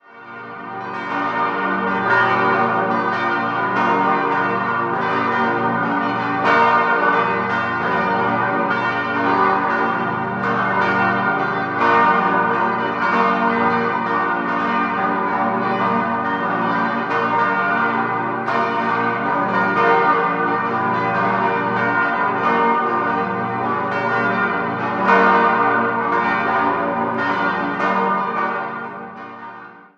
Etwas außerhalb des Altstadt steht die 1927 bis 1929 errichtete Josefskirche mit ihren markanten Doppeltürmen und dem 73 Meter langen Kirchenschiff. Die Architekten waren Michael Kurz und Thomas Wechs. 6-stimmiges Geläut: g°-b°-d'-f'-g'-b' Die Glocken wurden 1988 von Rudolf Perner in Passau gegossen.